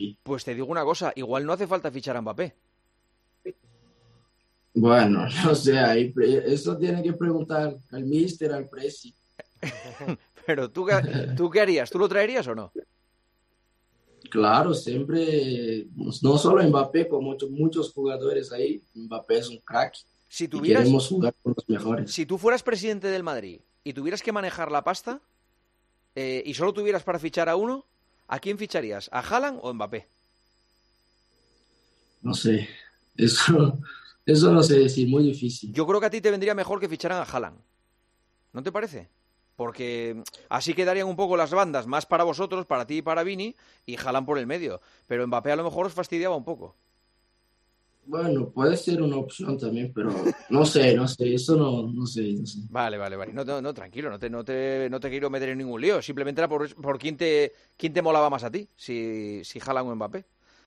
El extremo brasileño pasó por los micrófonos de El Partidazo de COPE desde la concentración de la Selección Brasileña y habló sobre la posible llega del francés en un futuro.